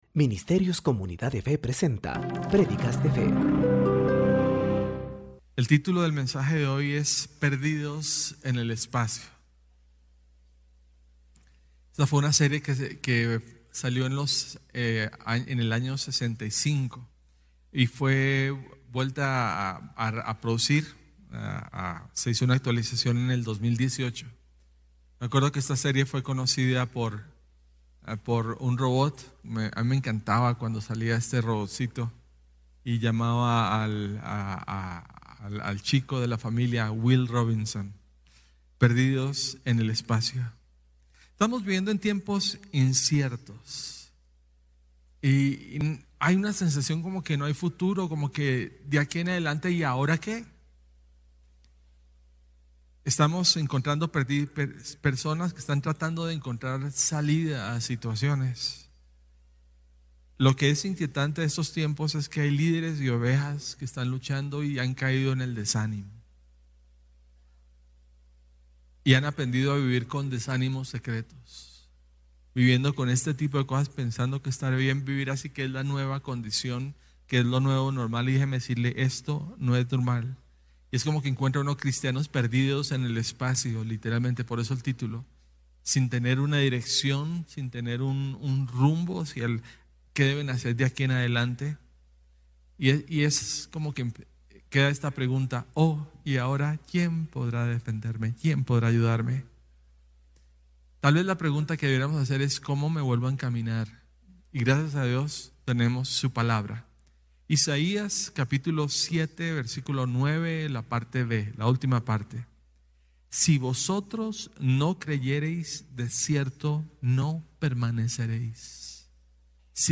Prédicas Semanales - Comunidad de Fe